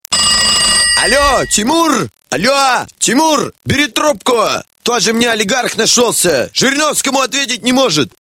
Рингтоны пародии